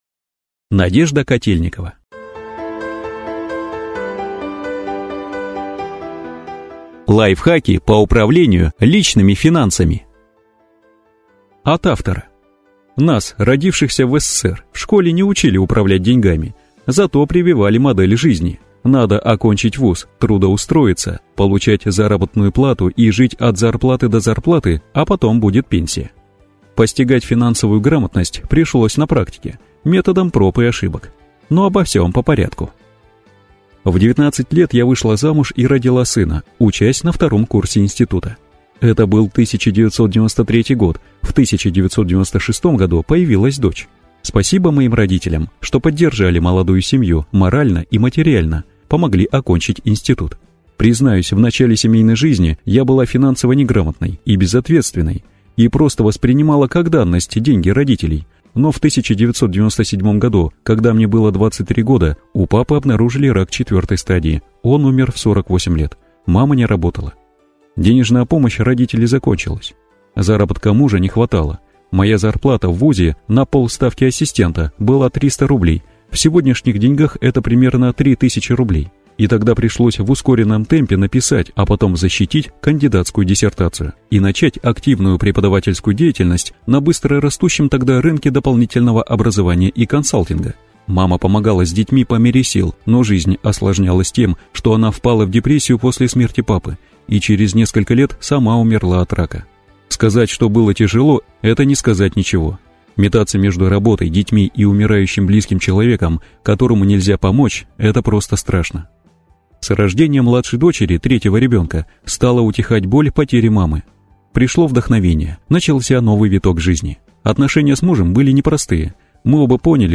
Аудиокнига Лайфхаки по управлению личными финансами | Библиотека аудиокниг